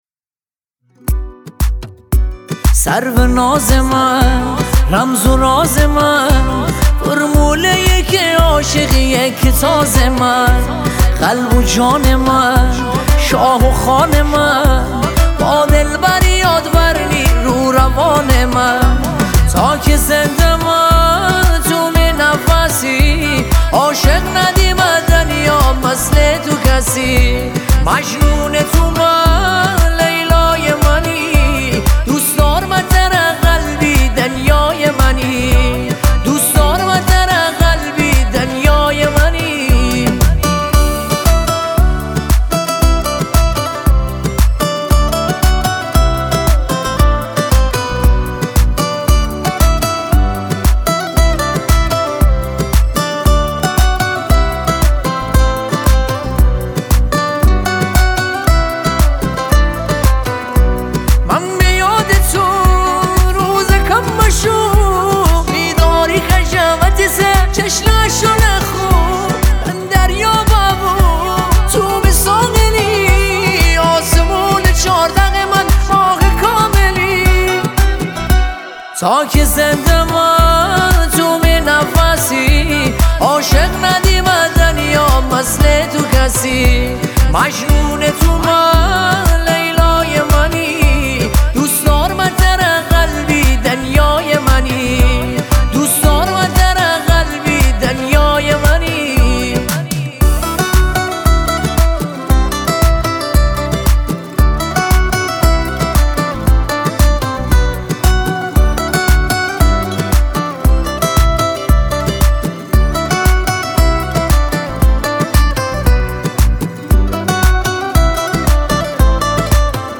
اهنگ شمالی